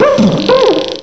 cry_not_trubbish.aif